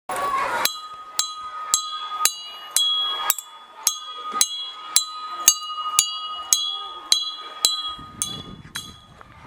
sino e uma vareta, com um toque pendular, anunciavam não a chegada de novos visitantes, mas o toque de saída das aulas para o recreio.